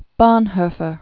(bônhœ-fər), Dietrich 1906-1945.